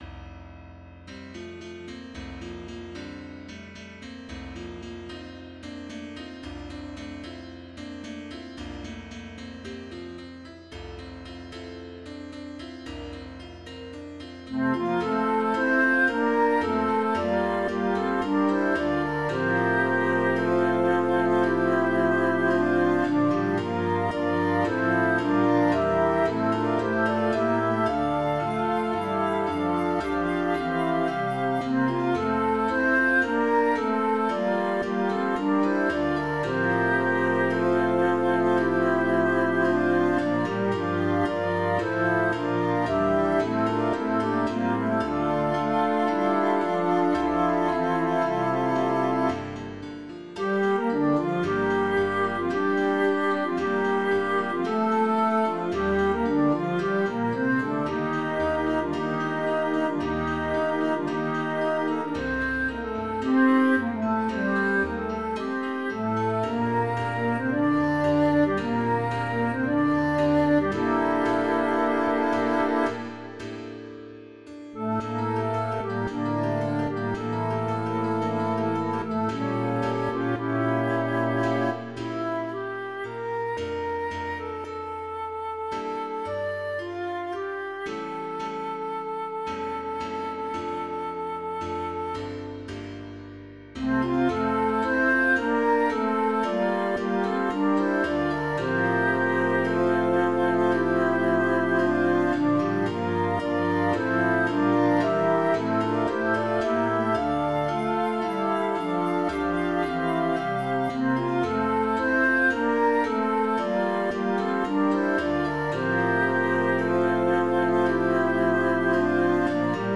3CHORABas1.mp3